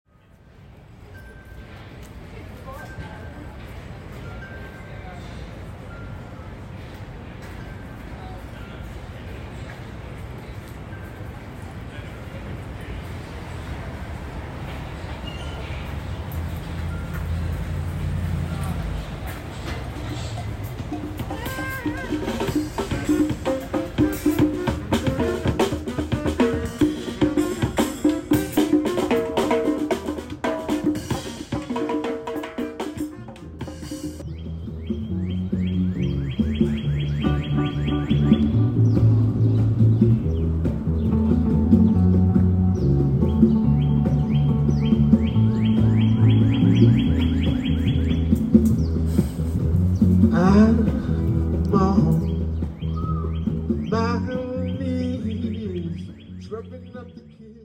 Someone has hung windchimes on a branch, and they blow in the breeze.
An Afro-Cuban ensemble performs, complete with two saxophonists, a man seated behind a drum set, and two percussionists with maracas and a tumba drum.
An unseen cardinal chirps faintly in the distance in response. As I enter each space, I am struck by how different, how unexpected, and often how loud their sounds are.